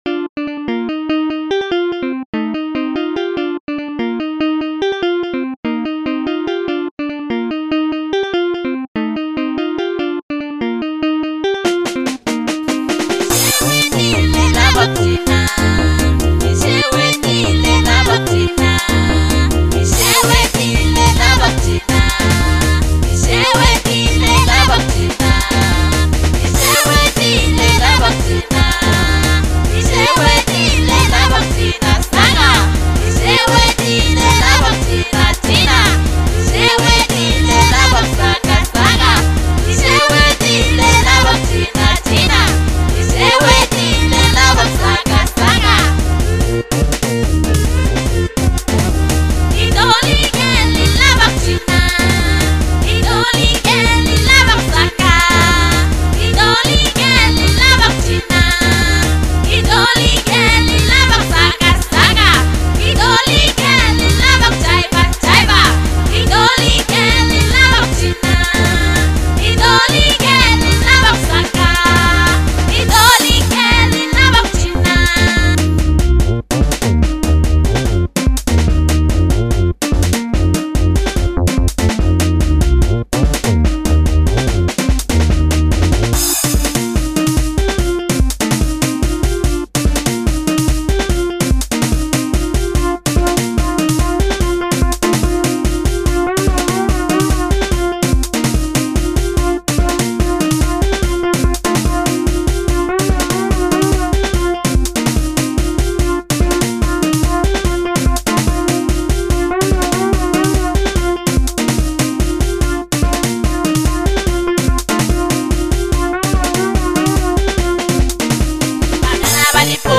04:13 Genre : African Kwasa Size